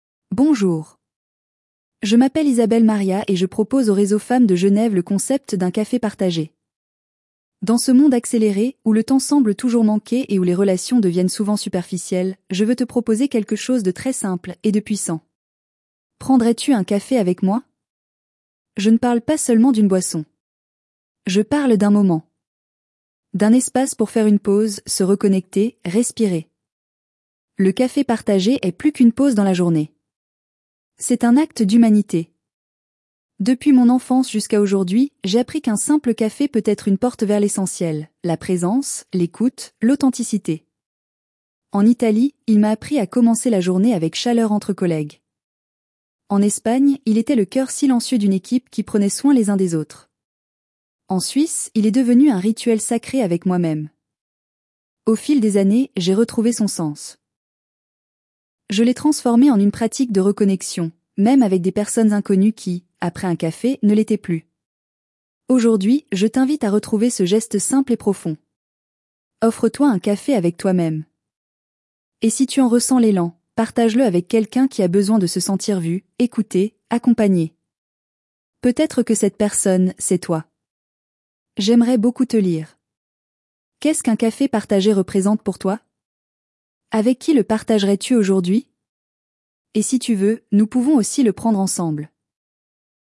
un texte lu